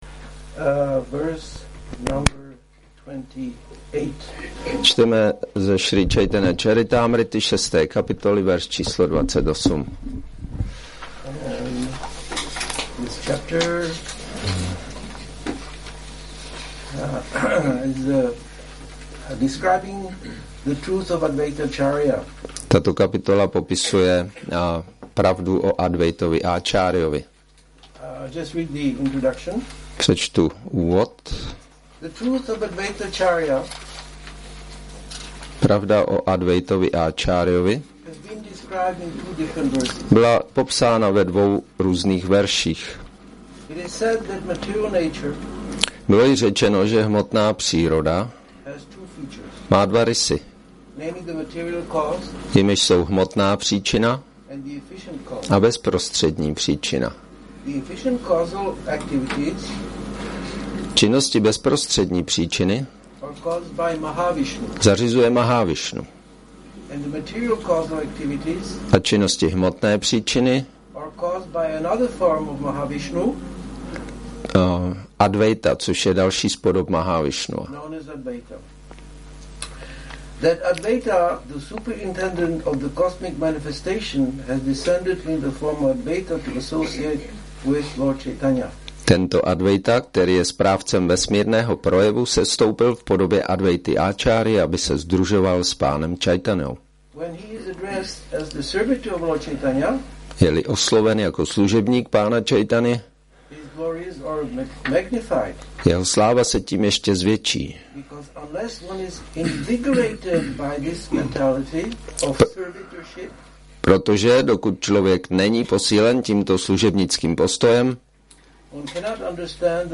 Přednáška